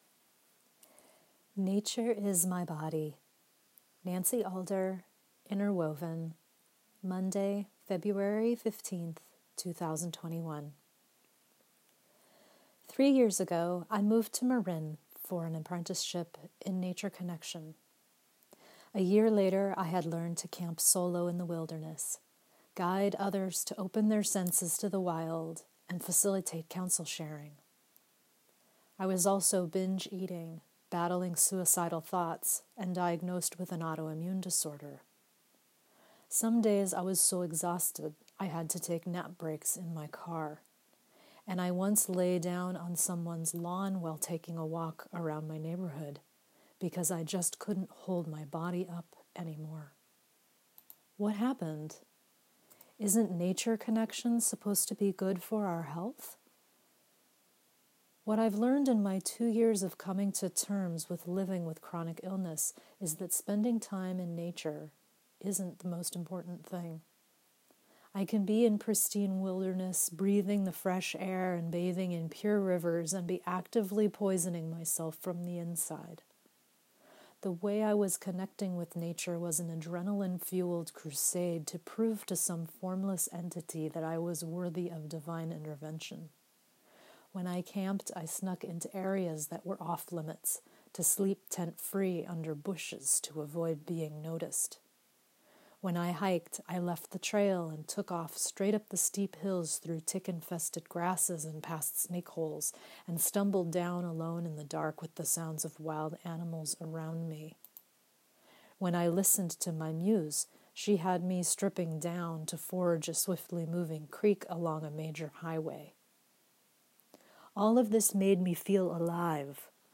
Enjoy this 11-minute read or let me read it to you via the recording at the beginning of the post on my website.